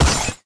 snd_glass.ogg